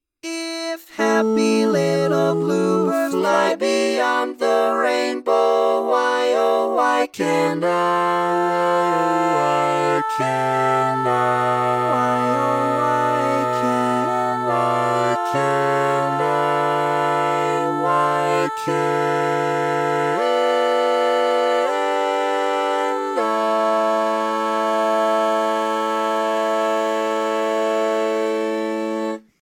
Key written in: A Major
Type: SATB